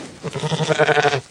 PixelPerfectionCE/assets/minecraft/sounds/mob/sheep/say2.ogg at mc116